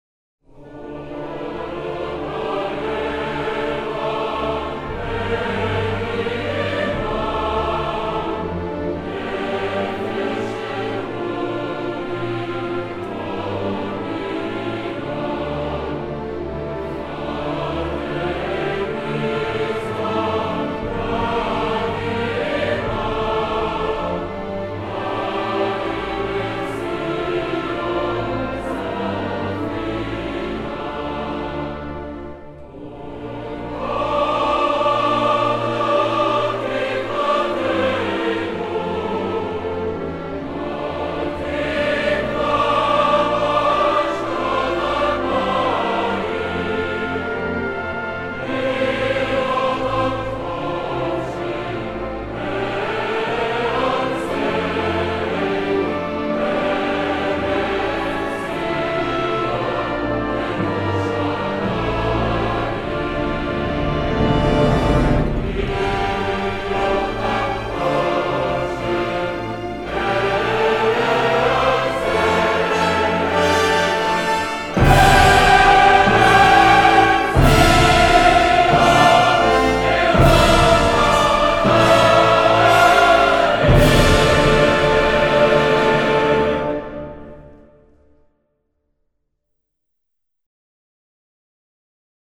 the Israel national anthem.